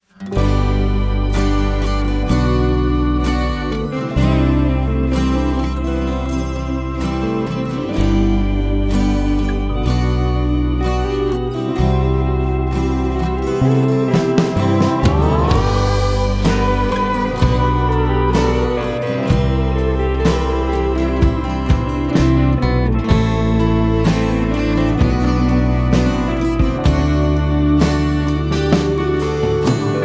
Lead Vocals and Rhythm Guitar
Saxophone